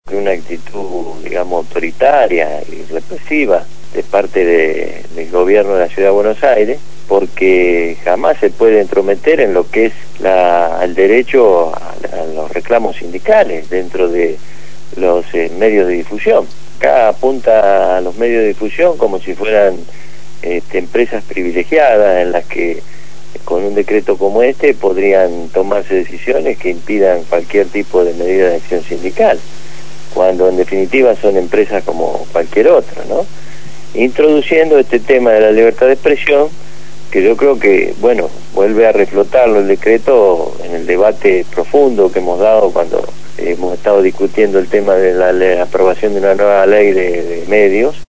Lo hizo en el programa «Punto de partida» (Lunes a viernes de 7 a 9 de la mañana) por Radio Gráfica FM 89.3